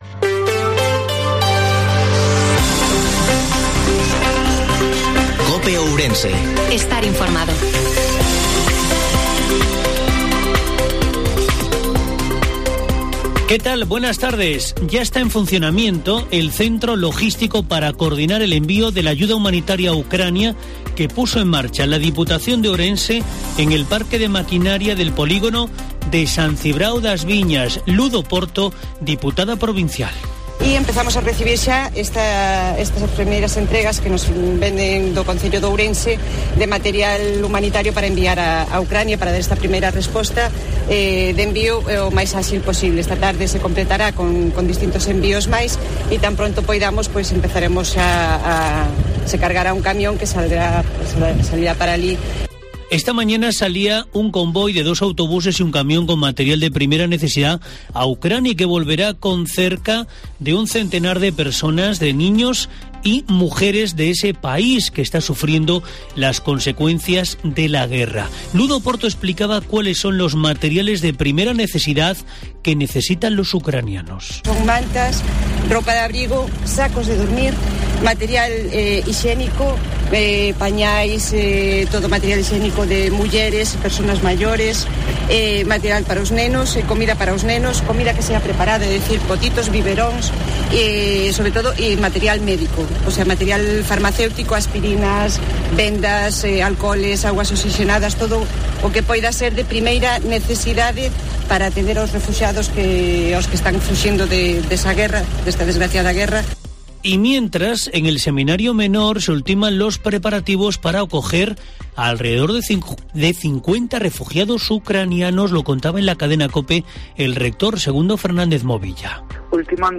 INFORMATIVO MEDIODIA COPE OURENSE 14/03/22